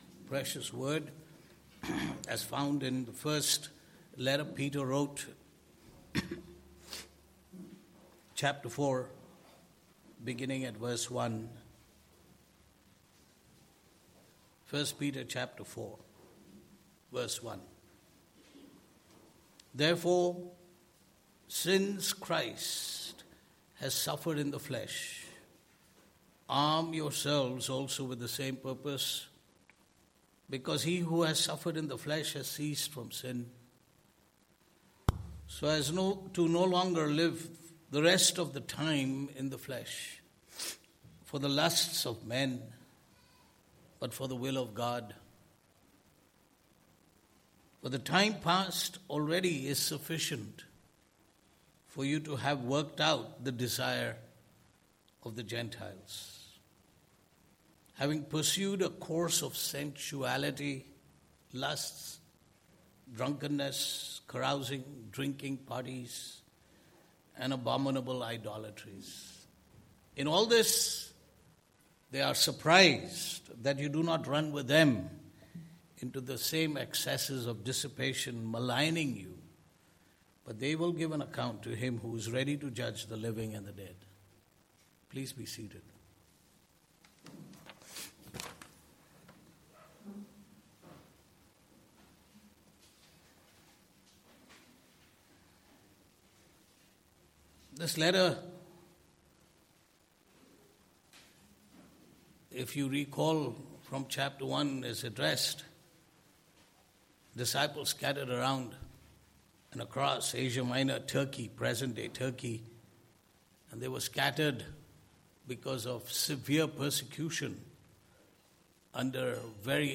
Passage: 1 Peter 4: 1-6 Service Type: Sunday Morning